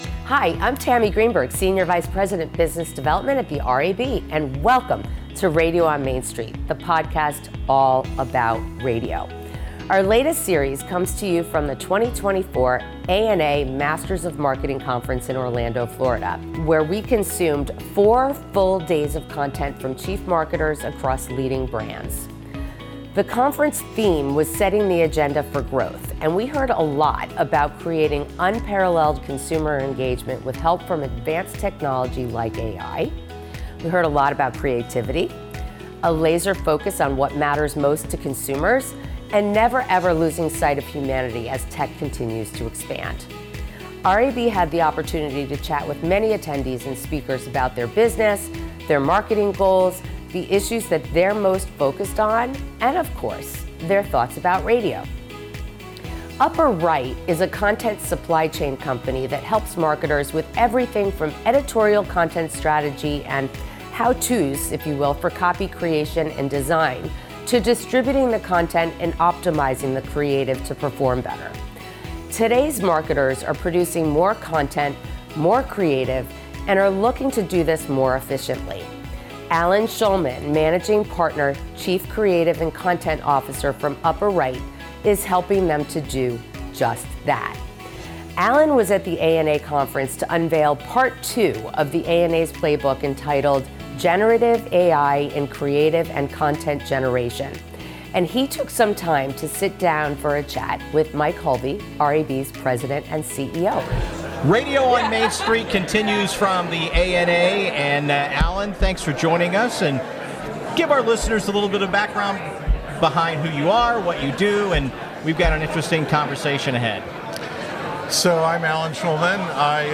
As a new feature, we are now including a video version of this interview on YouTube.